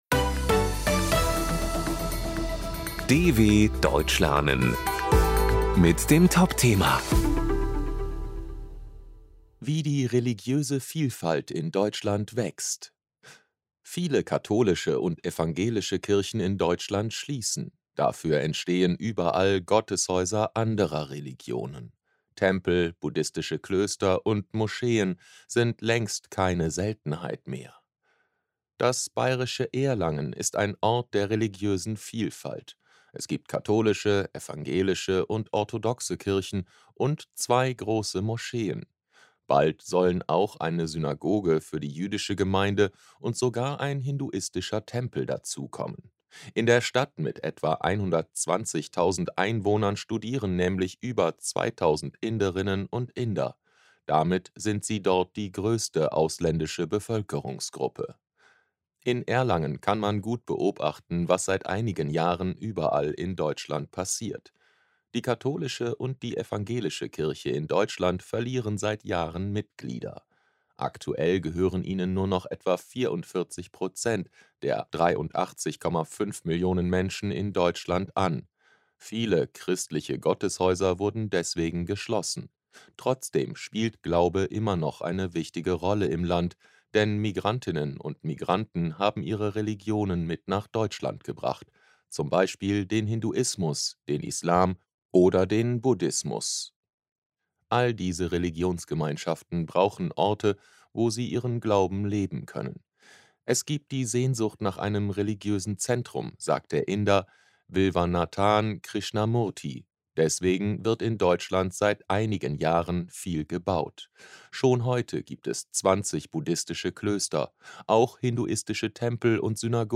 B1 | Deutsch für Fortgeschrittene: Deutsch lernen mit Realitätsbezug: aktuelle Berichte der Deutschen Welle – leicht verständlich und mit Vokabelglossar.